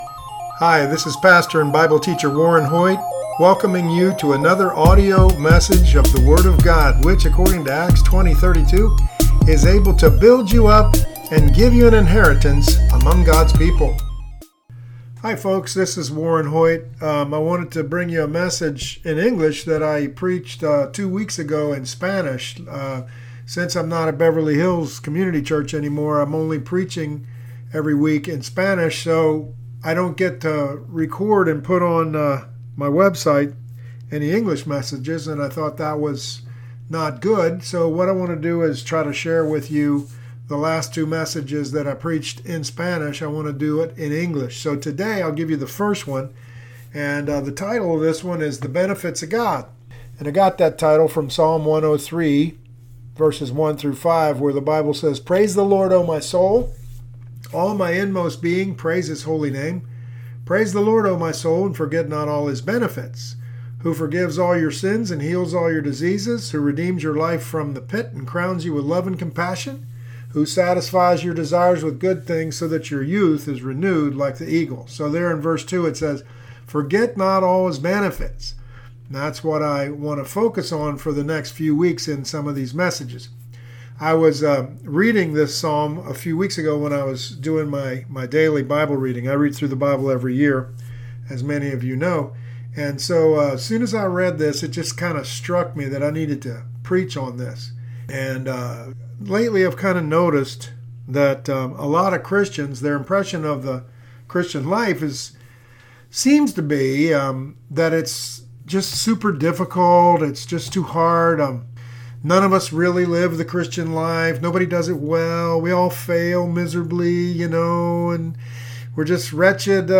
I’m not preaching regularly in English anymore, and some of you can’t understand my Spanish messages, so I decided to bring you the latest series in English, a teaching series based on Psalm 103.